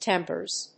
発音記号・読み方
/ˈtɛmpɝz(米国英語), ˈtempɜ:z(英国英語)/